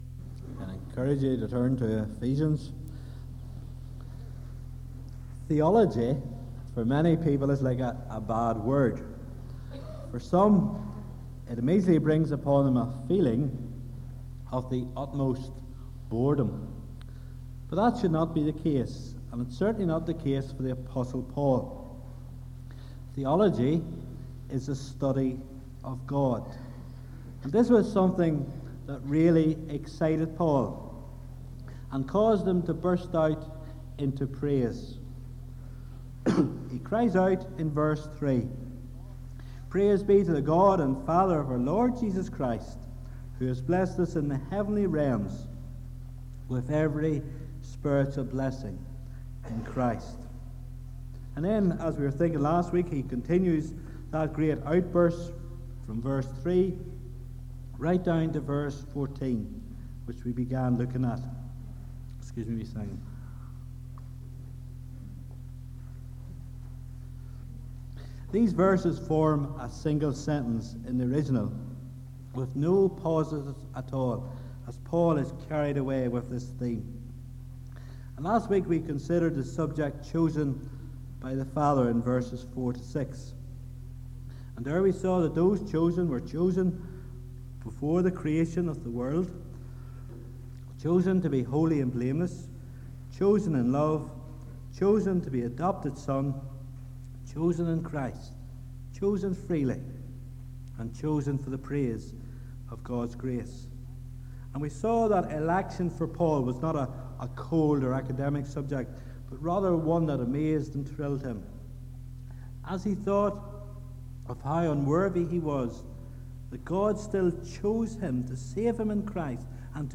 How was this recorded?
Please note: this sermon was preached before Brookside adopted the English Standard Version as our primary Bible translation, the wording above may differ from what is spoken on the recording.